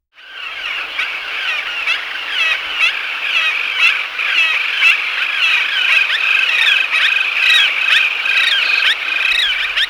cassin-s-auklet.wav